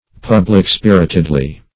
[1913 Webster] -- Pub"lic-spir`it*ed*ly, adv. --
public-spiritedly.mp3